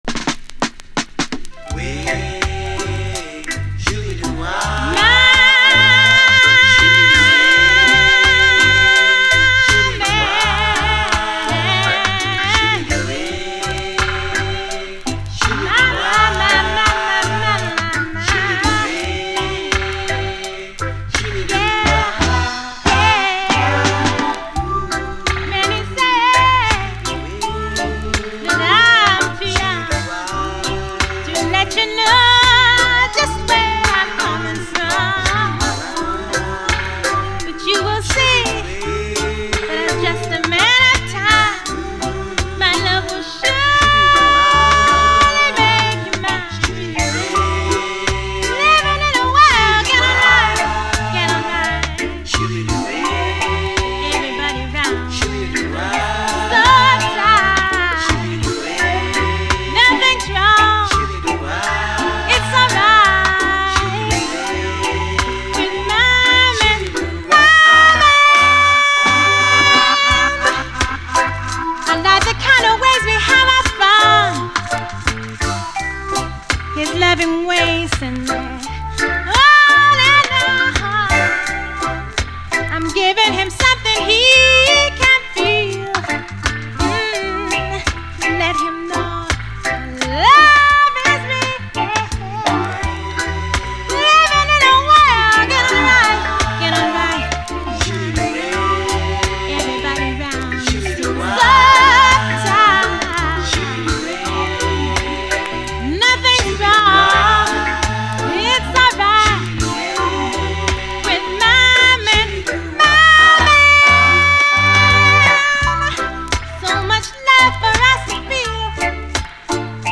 REGGAE
パーソナルなザックリ感が魅力です。